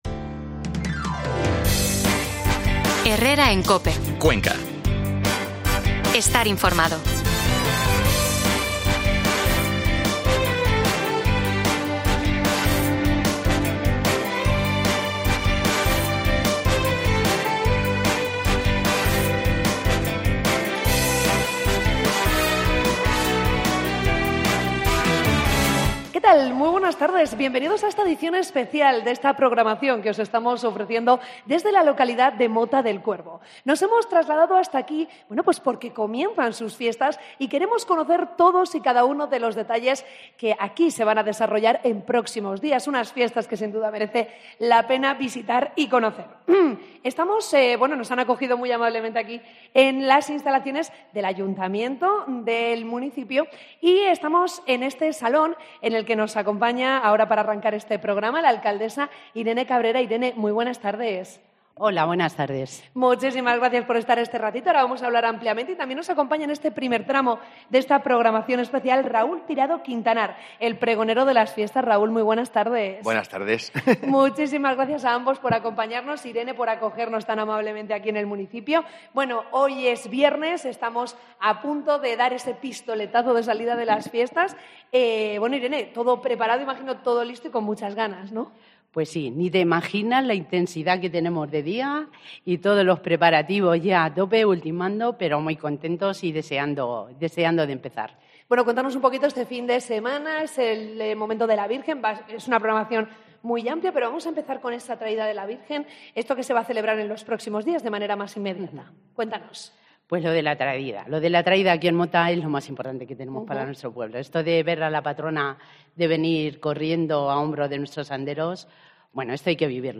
AUDIO: Escucha el programa especial desde Mota del Cuervo con motivo del inicio de las fiestas en honor a la Virgen de Manjavacas